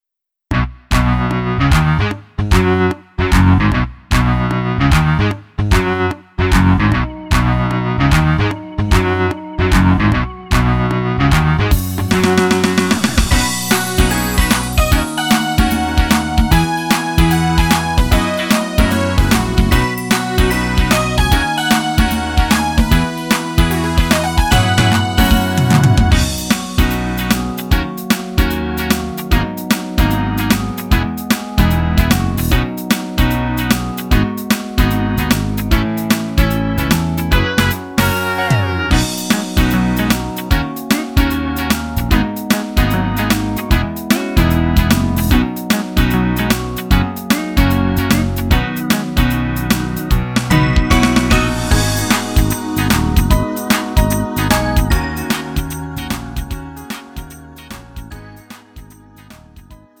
음정 원키
장르 가요 구분 Lite MR